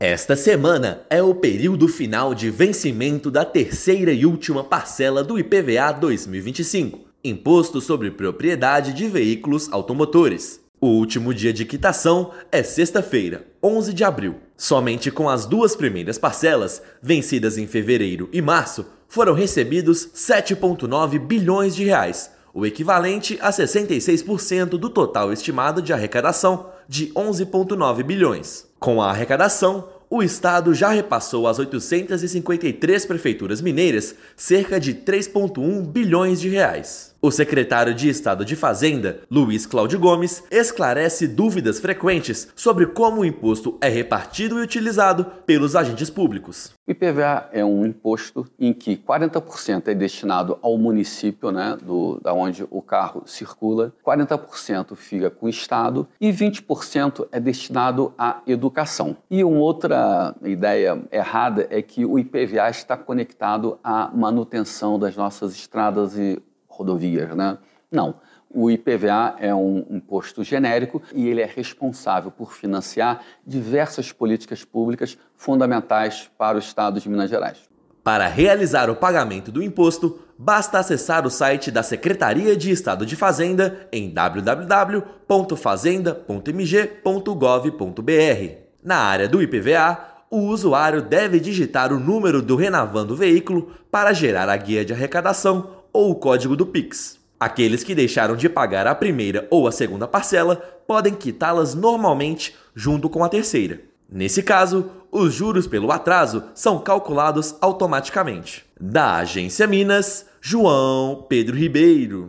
Valor arrecadado com o imposto é revertido em políticas públicas do Estado e dos municípios. Ouça matéria de rádio.